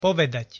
pronunciation_sk_povedat.mp3